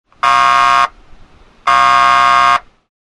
Civil Defense Sirens
sirens_airhorn.mp3